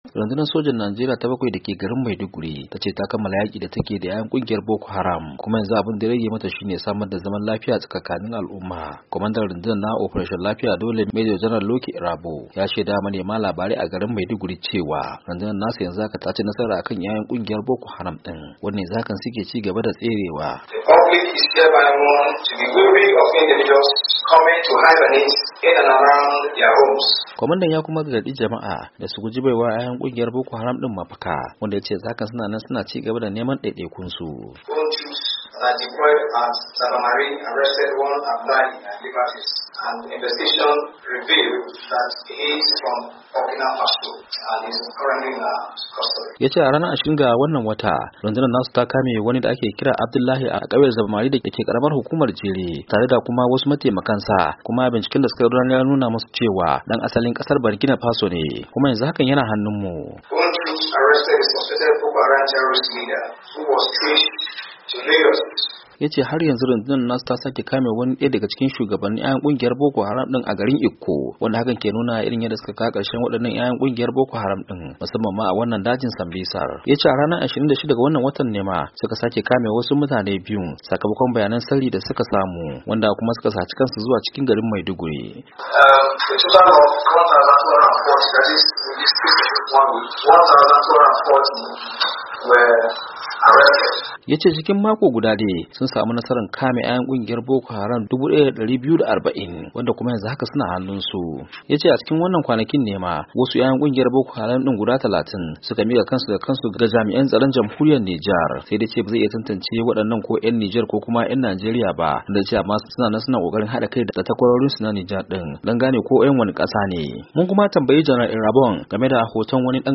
Kwamandan rundunar Operation Lafiya Dole, Janal Lucky Irabor, shine ya shaidawa manema labarai hakan, inda kuma ya gargadi jama’a da su guji baiwa mayakan Boko Haram mafaka, kuma yace yanzu haka suna nan suna neman mayakan a duk inda suke.